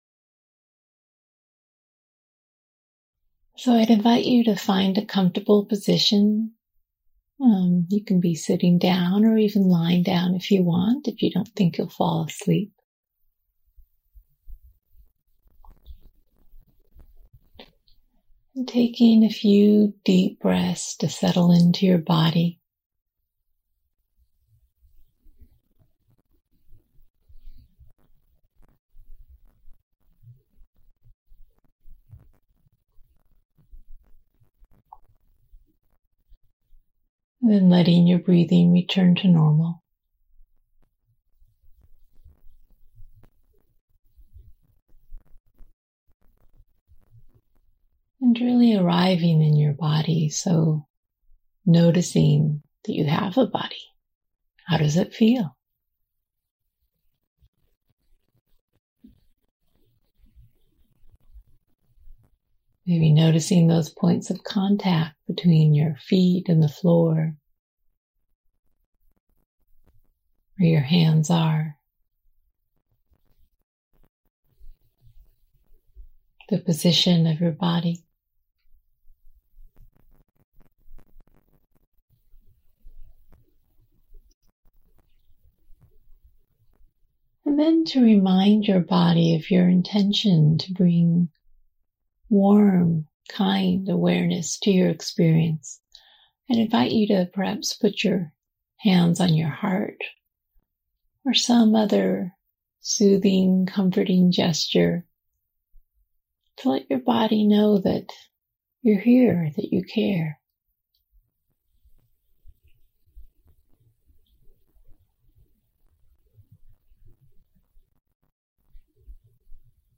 This guided visualization helps you meet an inner compassionate friend who can help you at any moment.